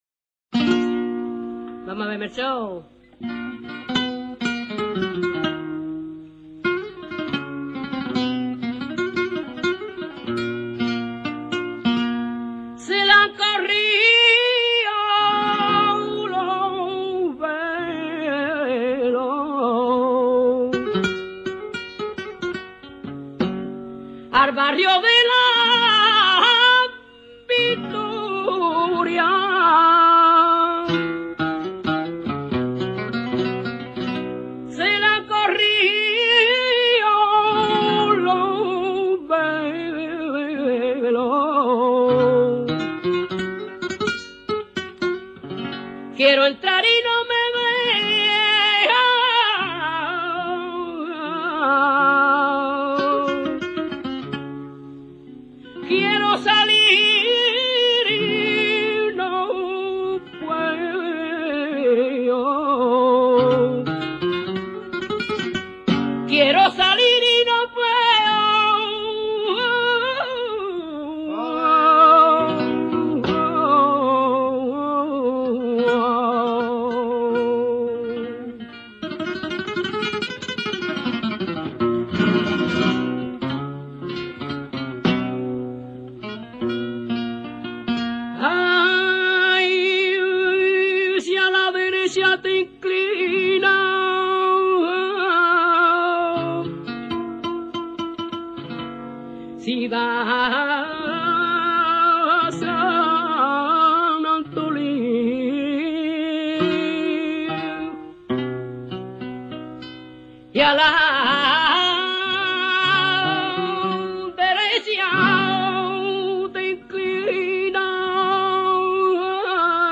No es hoy, cante para bailar. Su acompa�amiento de guitarra se realiza toc�ndola siempre por arriba, y presenta una gran riqueza mel�dica y un profundo sentimiento.
malaguena.mp3